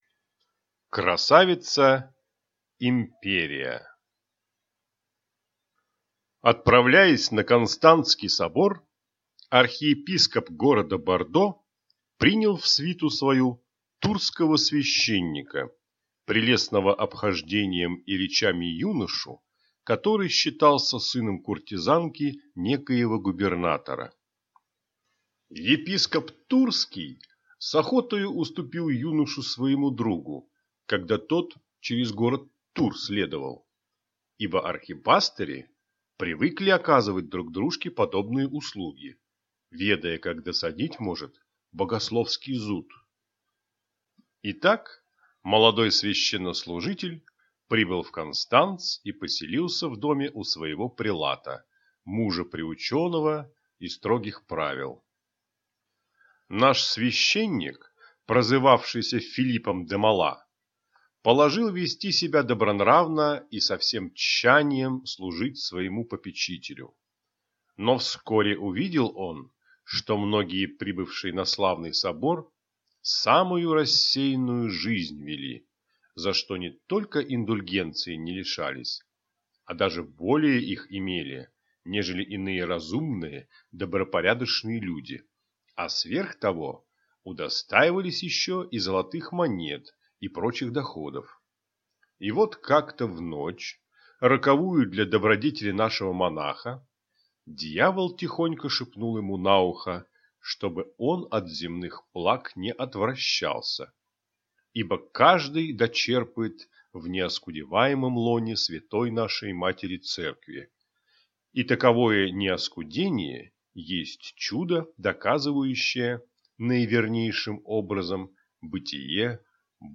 Аудиокнига Невольный грех (сборник) | Библиотека аудиокниг